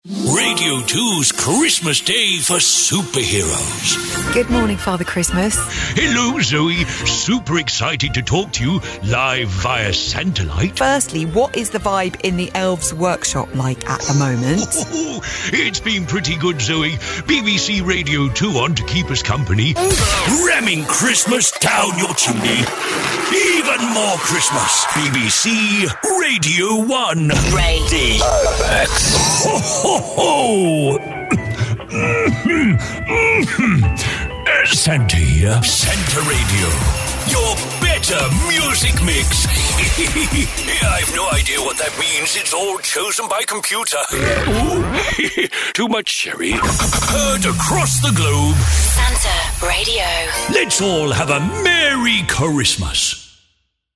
The UK’s Leading Voice of Santa, Ho Ho Ho!
Authentic, warm, joyful, and instantly recognisable, no imitations here. Just the real, British Father Christmas voice trusted by major brands every year.